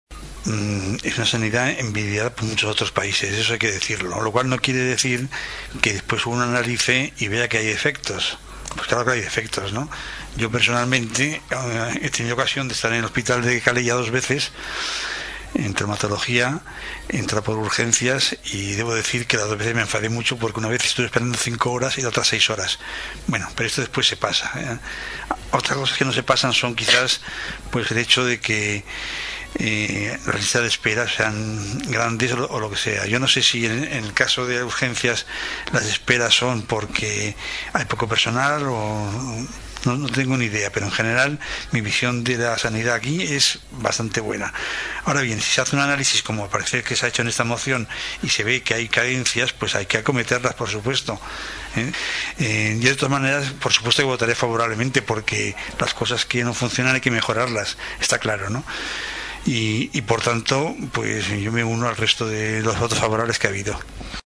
El regidor no-adscrit, Jose Carlos Villaro, va descriure la situació actual de la sanitat, amb llargues llistes d’espera.